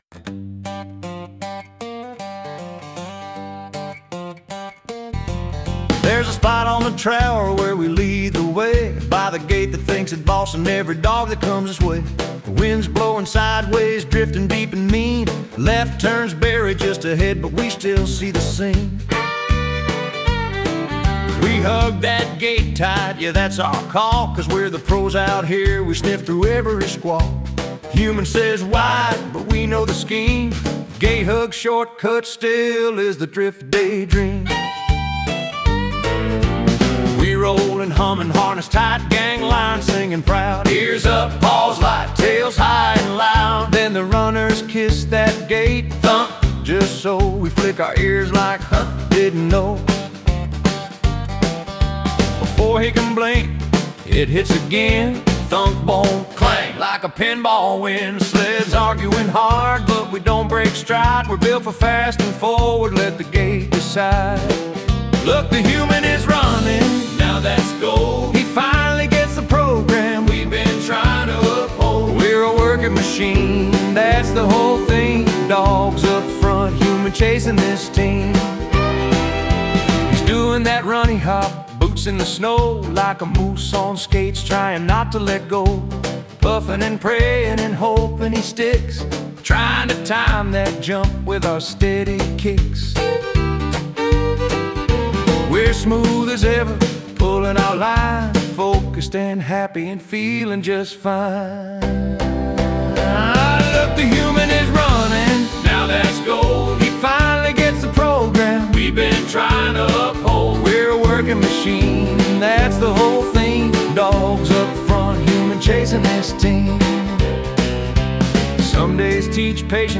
We’ve created this special collection of AI-generated songs to further enrich the stories shared here.